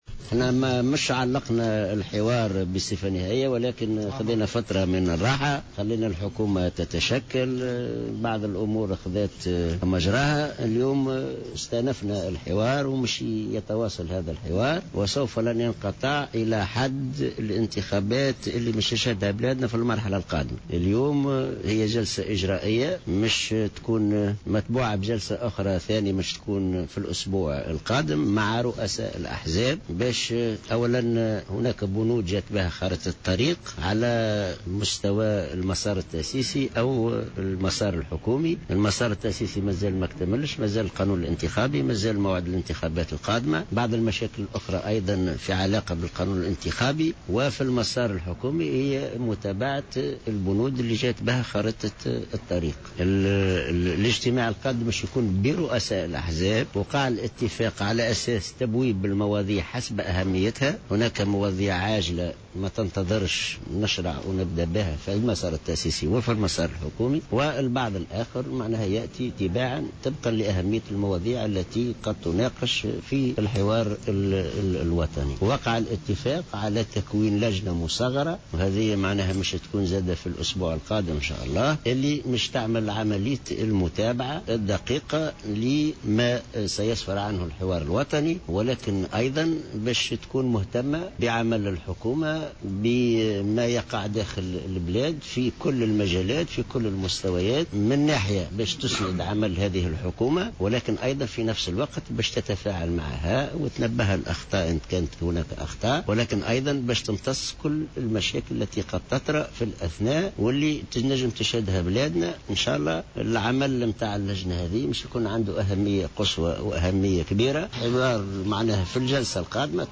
قال الأمين العام للاتحاد العام التونسي للشغل في تصريح لجوهرة "اف ام" على هامش استئناف جلسات الحوار الوطني اليوم الاربعاء 26 فيفري 2014 أن الحوار الوطني لن ينقطع الى حين اجراء الإنتخابات القادمة مؤكدا أنه تم تعليق الحوار مؤقتا لأخذ قسط من الراحة .